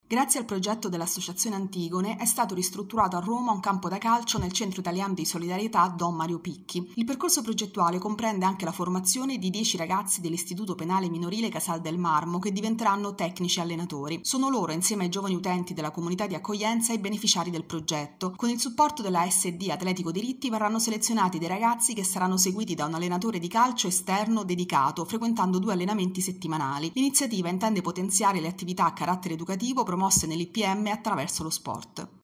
Il progetto “Squadra dentro: sport e carcere” si rivolge ai giovani dell’Ipm di Casal del Marmo e ai giovani utenti del Centro italiano di solidarietà. Il servizio